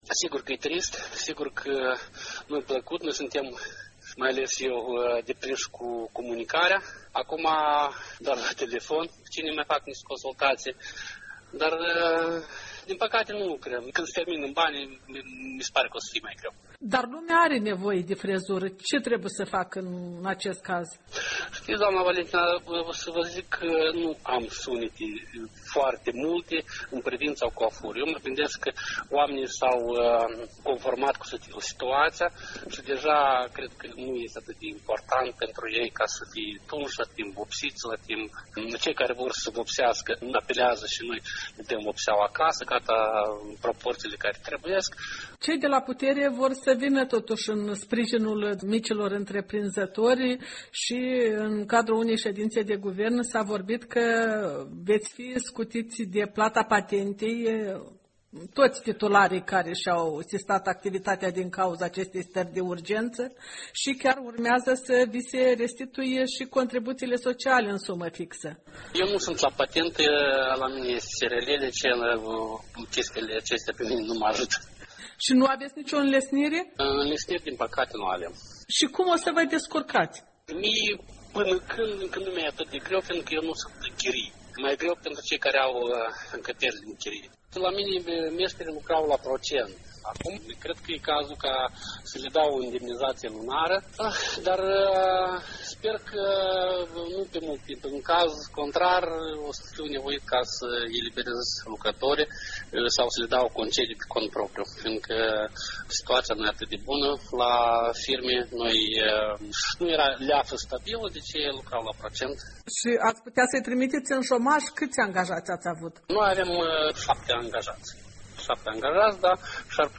Pandemia de coronavirus loveste sectorul servciilor din greu, mai ales că este un sector dominat de întreprinderi mici sau întreprinzători individuali: de la vânzătorul de legume la proprietarul unui sofisticat salon de frumusețe. De vorbă cu stilistul